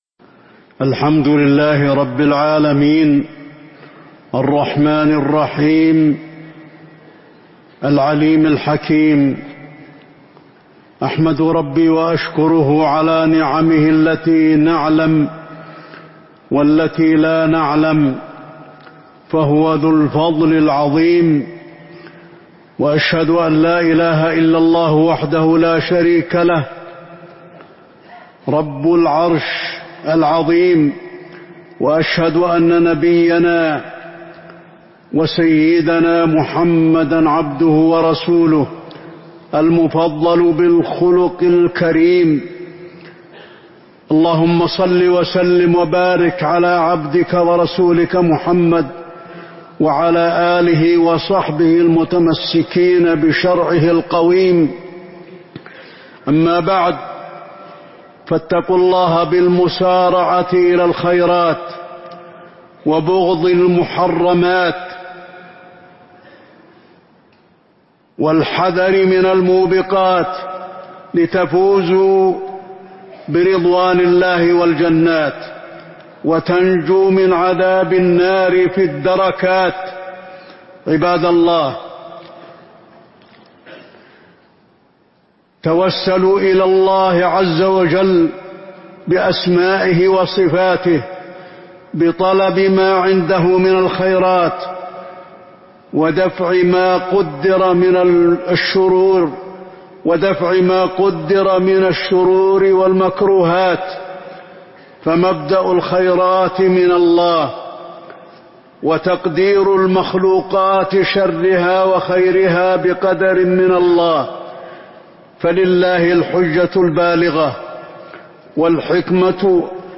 تاريخ النشر ١٤ رجب ١٤٤٥ هـ المكان: المسجد النبوي الشيخ: فضيلة الشيخ د. علي بن عبدالرحمن الحذيفي فضيلة الشيخ د. علي بن عبدالرحمن الحذيفي رحمة الله تعالى